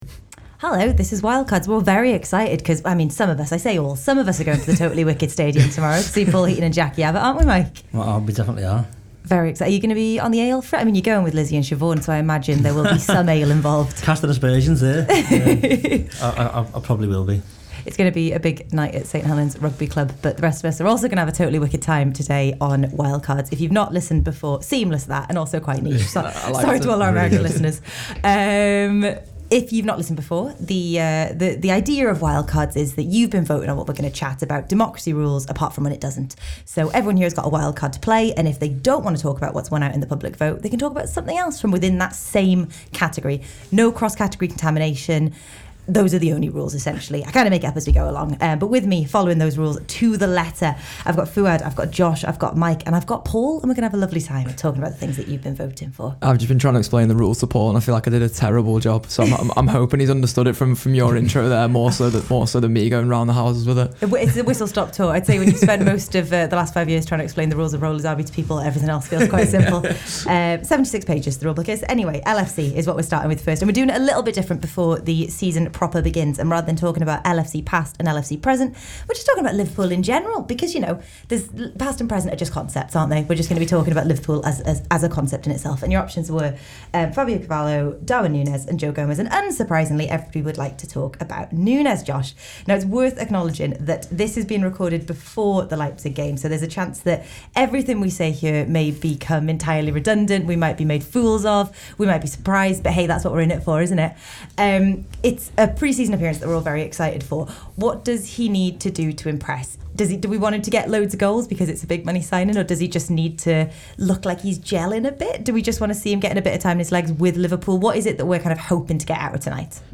The panel discuss Darwin Nunez (before the RB Leipzig game), the Women’s Euros (after England’s win), Novak Djokovic and Love Island winners.